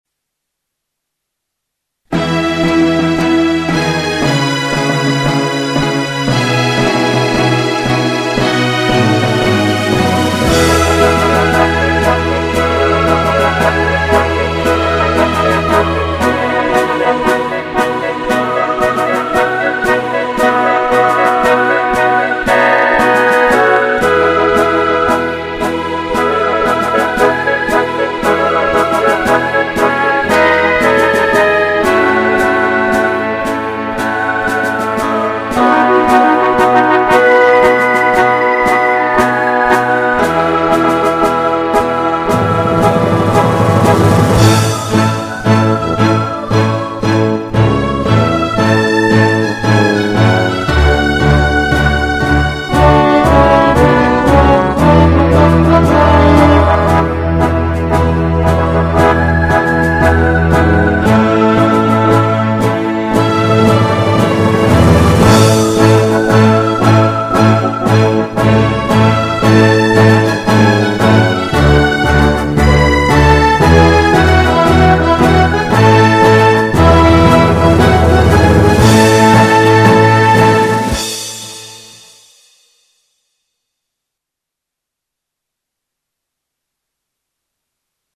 Himno solo música